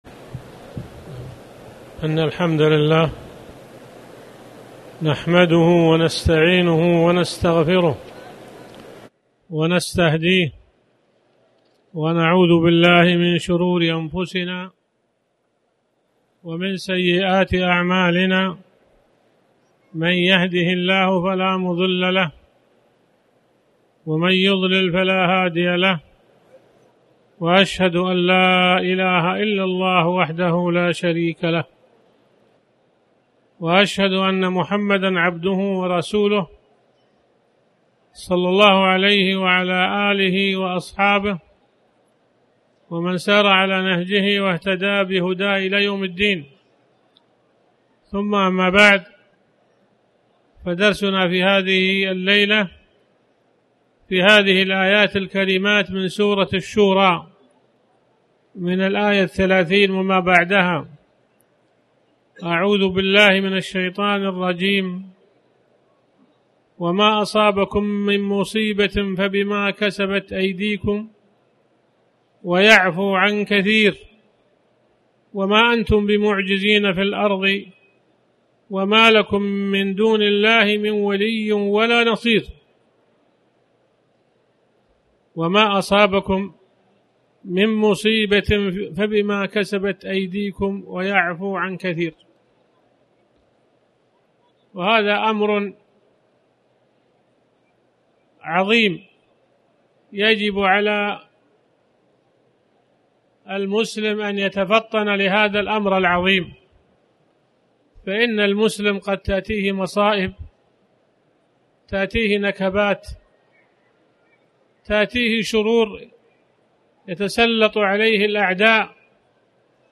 تاريخ النشر ٢٦ محرم ١٤٣٩ هـ المكان: المسجد الحرام الشيخ